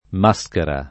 [ m #S kera ]